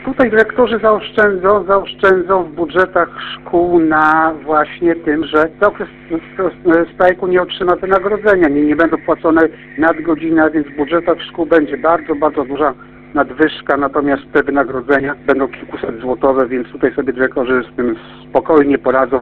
– Wynagrodzenie takiej osobie ma zapewnić dyrektor szkoły – dodaje kurator.